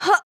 damage4.wav